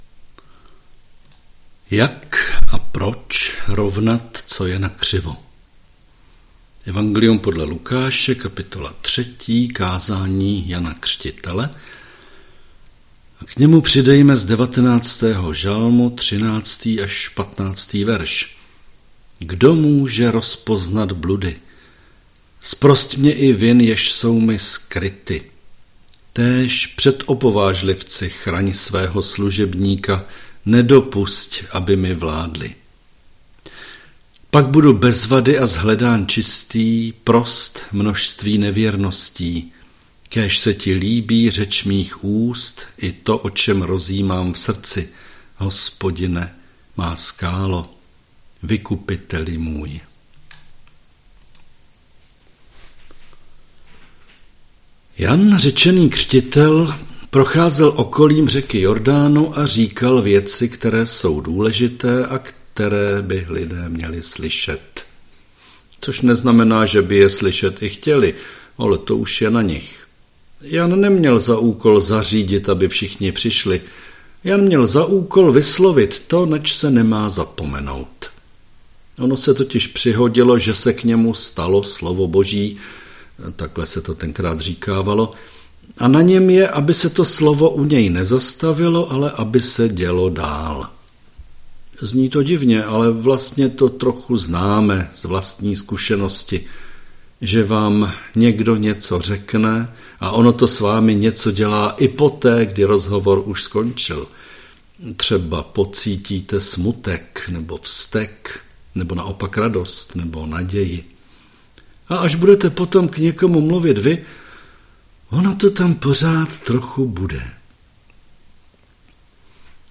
Přehled kázání